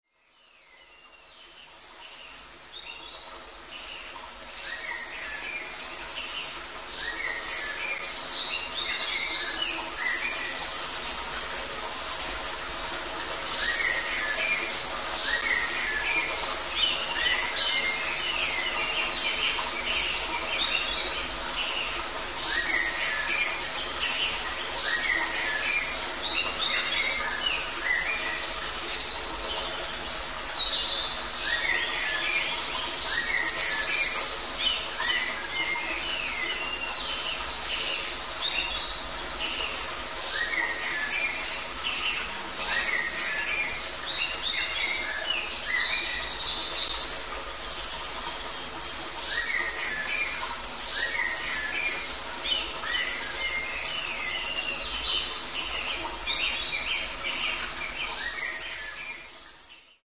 Forest Stream
Category: Animals/Nature   Right: Personal